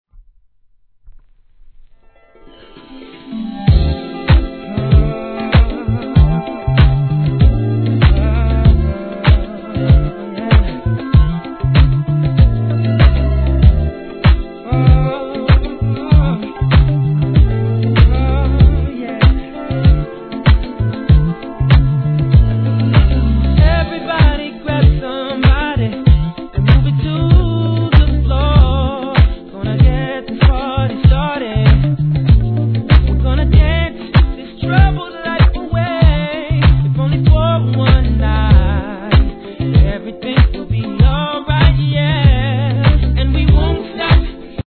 HIP HOP/R&B
大人なR&B好きには大推薦のスムースナンバー♪